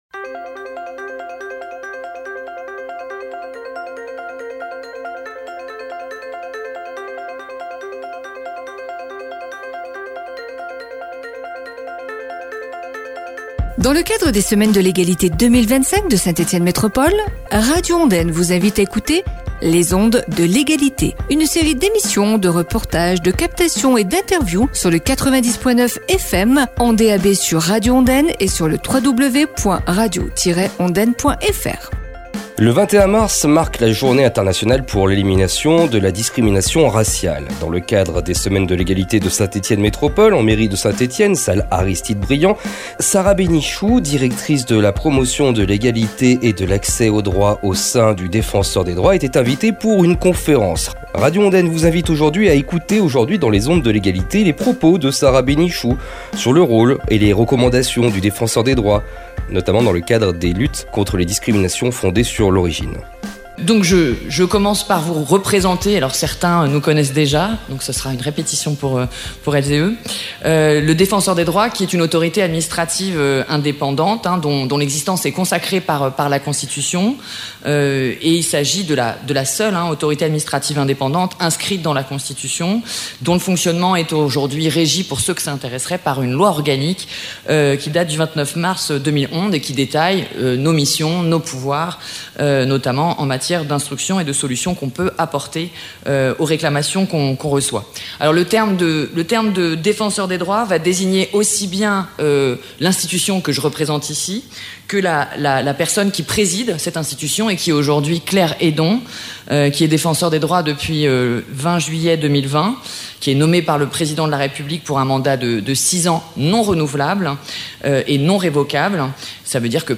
DEFENSEUR DES DROITS, conférence